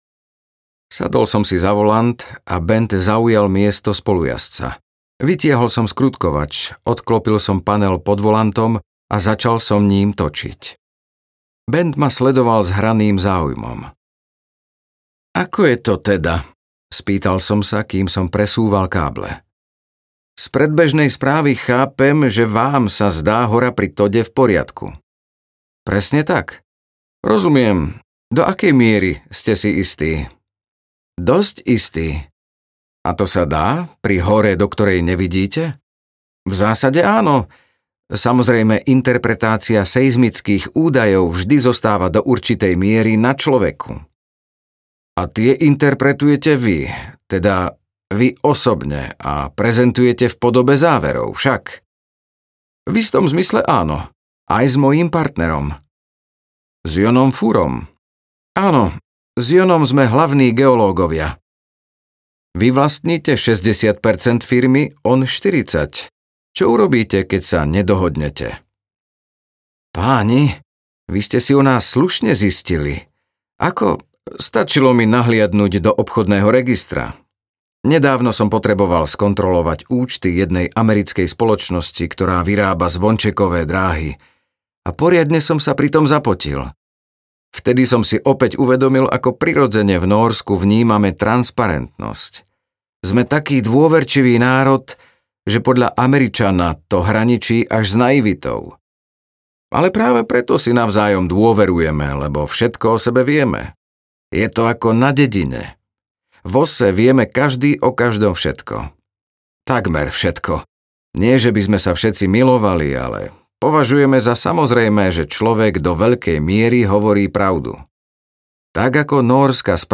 Vypočujte si ukážku audioknihy